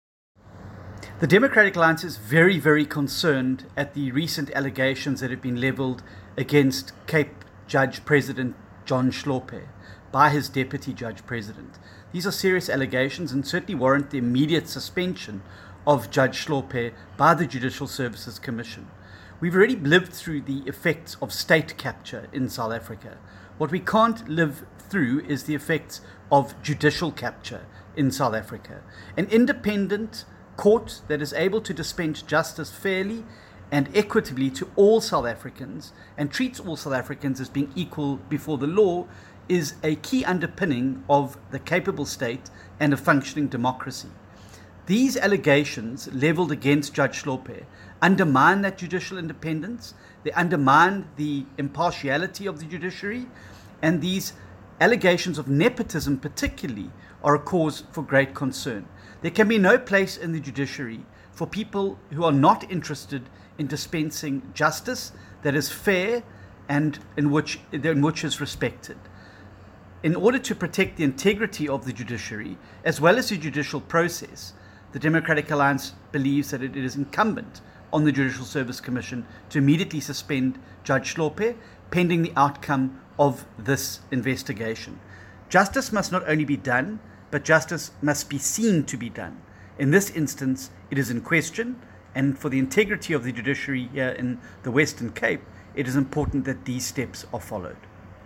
soundbite by Democratic Alliance Leader, John Steenhuisen MP